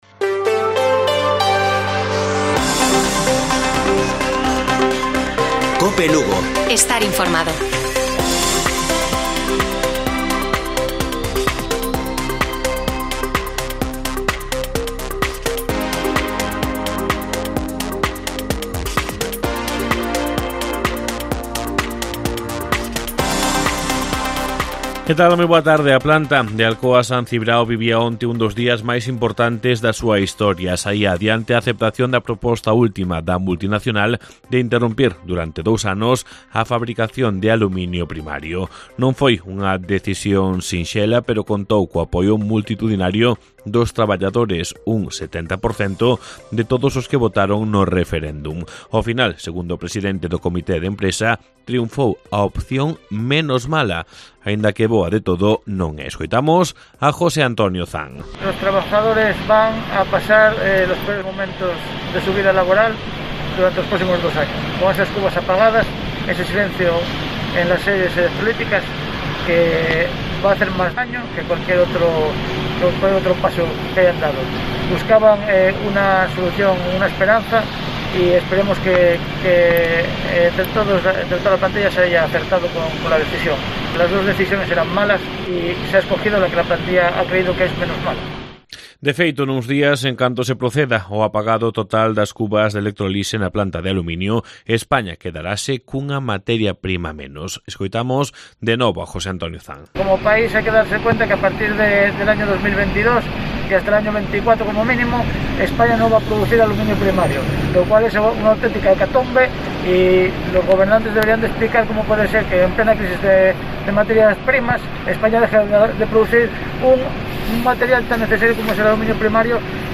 Informativo Mediodía de Cope Lugo. 29 de diciembre. 13:50 horas